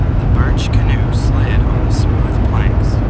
This algorithm aims to improve the intelligibility of a noisy speech signal without increasing the energy of the speech.
A linear filter that optimally redistributes energy according to a mutual information criterion is applied to the clean speech.
enhanced_mixture.wav